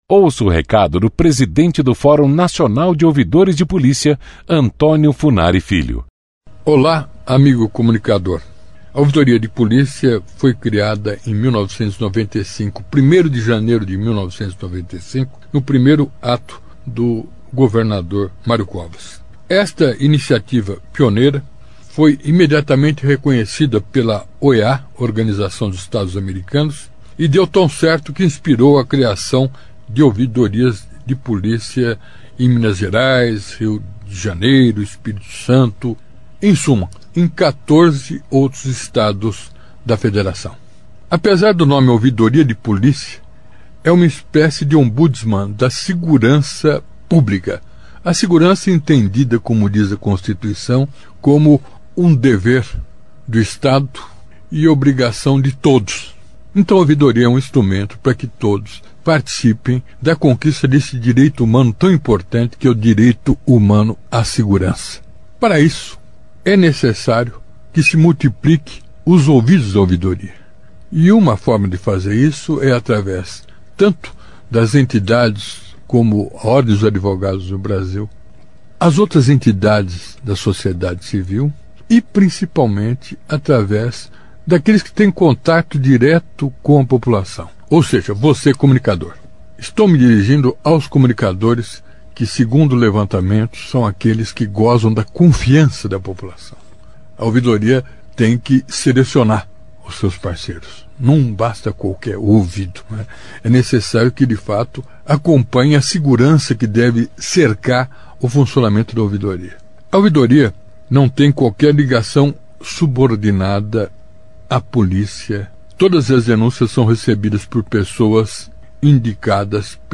Faixa 2 - Carta Falada de Antonio Funari Filho, Pres. Fórum Nacional de Ouvidores de Polícia (5:23)